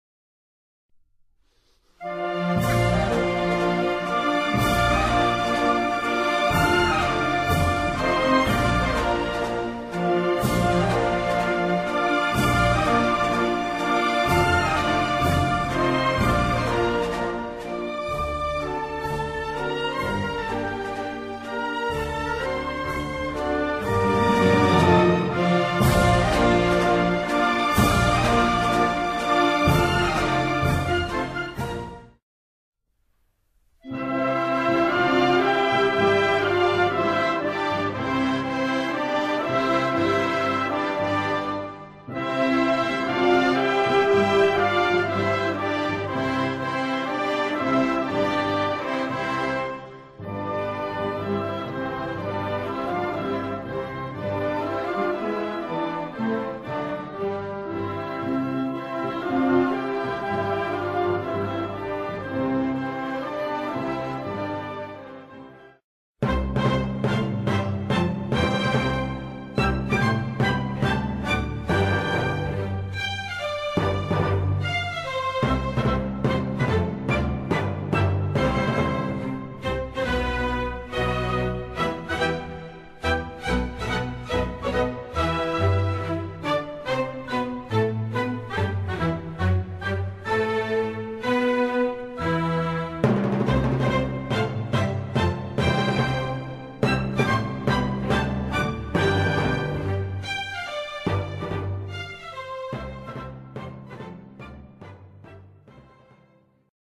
Sequenza-Inni-per-Rotary_orchestrale_SHORT.mp3